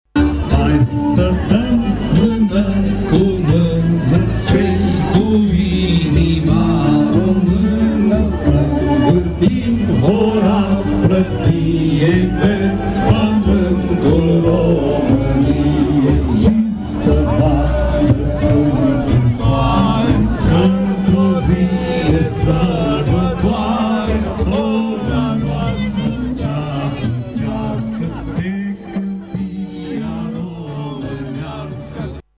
Apoi, după spectacol, ca de fiecare dată, participanții au încins tradiționala horă a Unirii, pe esplanada din fața clădirii Teatrului Național: